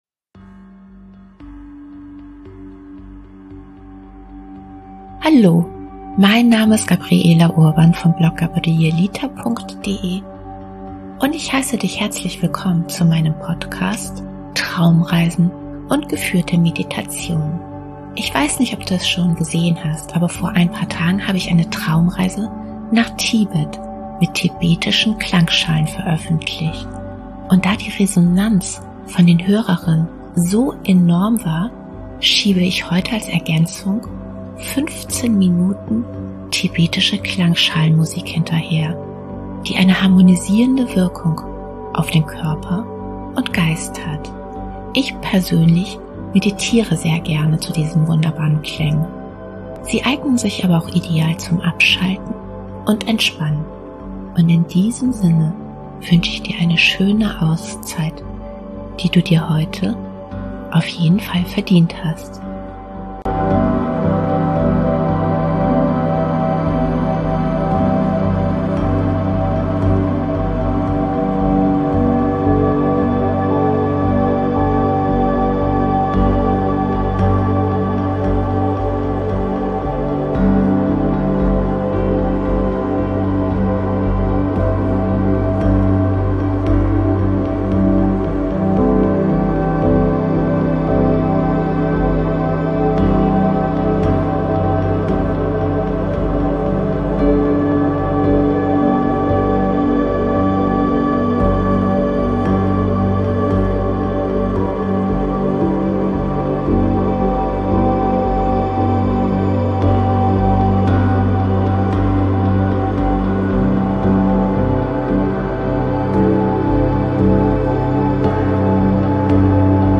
Tibetische Klangschalenmusik zum Entspannen & Meditieren
Ich persönlich meditiere sehr gerne zu diesen wunderbaren Klängen. Sie eignen sich aber auch ideal zum Abschalten und Entspannen.